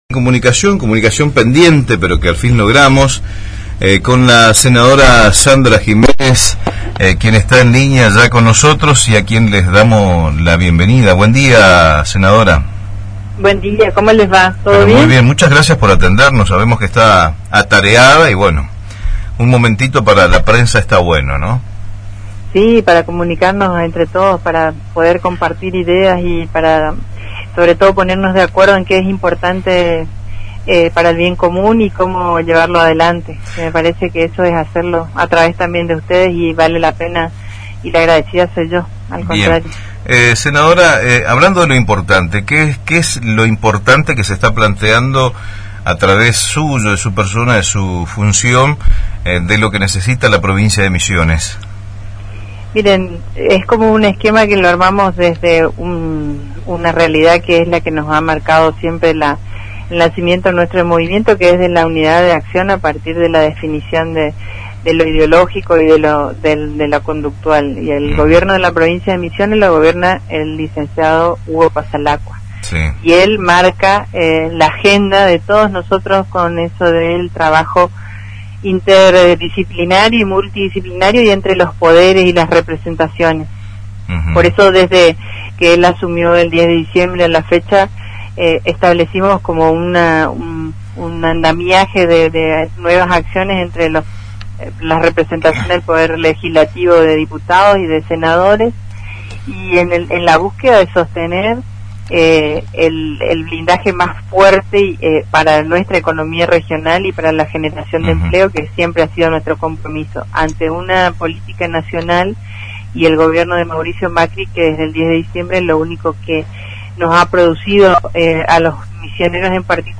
Así lo expresó la Senadora Sandra Giménez, en el Magazine de Cooperativa Argenta.